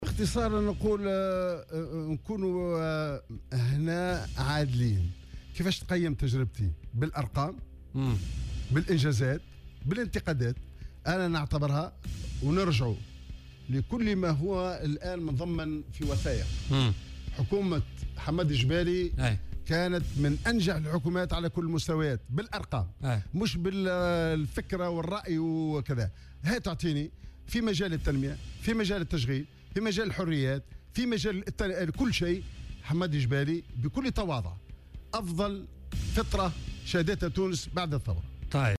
وأضاف في مداخلة له اليوم في برنامج "بوليتيكا" : " بالأرقام حكومتي كانت من أنجح الحكومات على كل المستويات..و بكل تواضع اعتبرها أفضل فترة شهدتها تونس بعد الثورة."